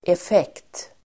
Uttal: [ef'ek:t]